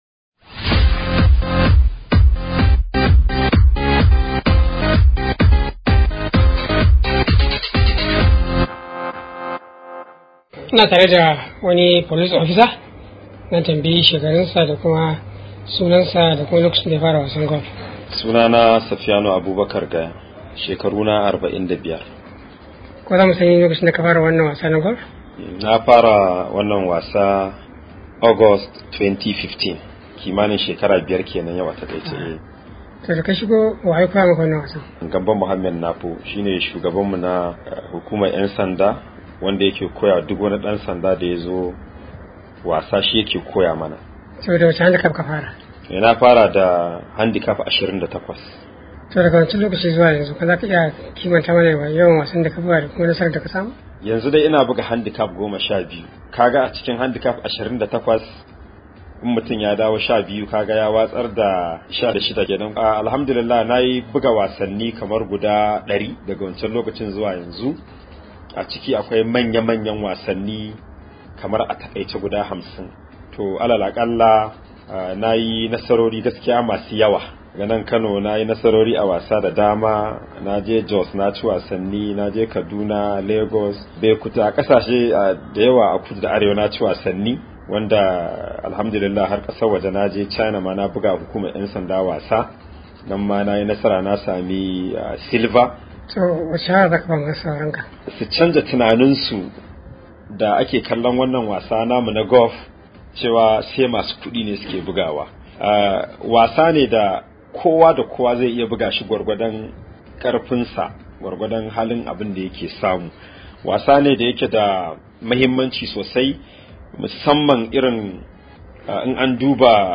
Daga filin wasa na kwallon Golf dake Kano Club